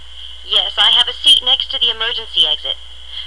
(加連線者為連音，加網底者不需唸出聲或音很弱。)